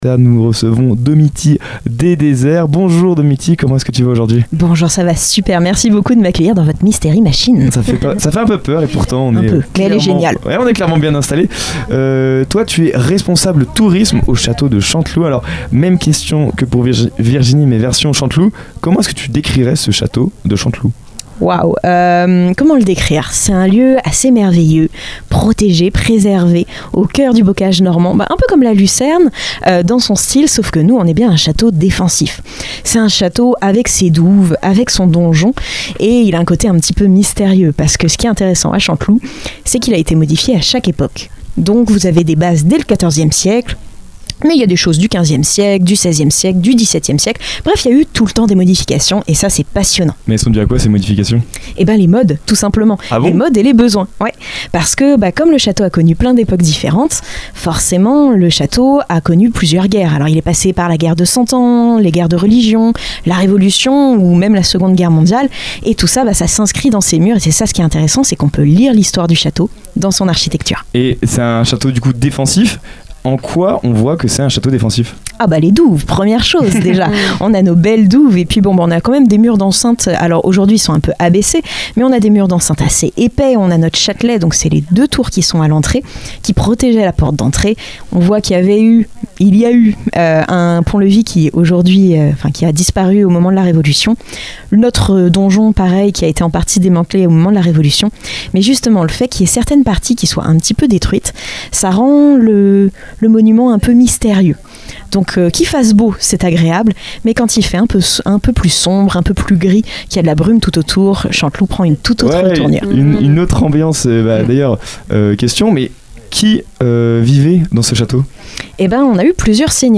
Elle évoque également les projets culturels, les événements et la volonté de transmettre l’histoire et l’âme du château au public. Une interview immersive qui met en lumière un site patrimonial remarquable, entre tradition, culture et valorisation du territoire normand.